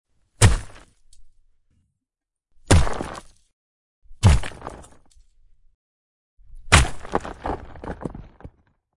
rock-smash-6304.mp3